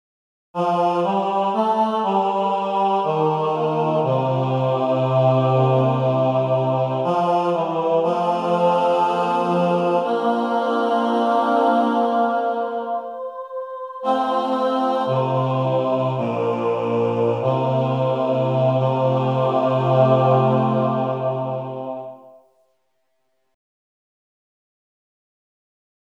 Key written in: F Major